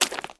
STEPS Swamp, Walk 13.wav